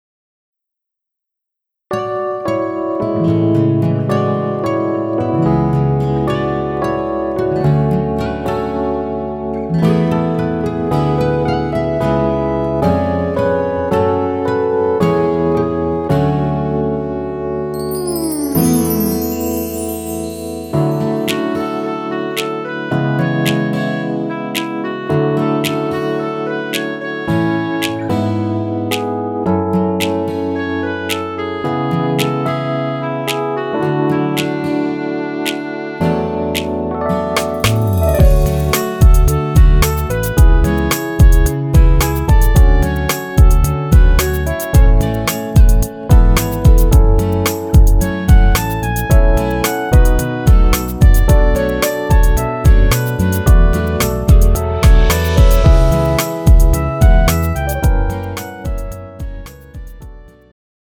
음정 -2키
장르 축가 구분 Pro MR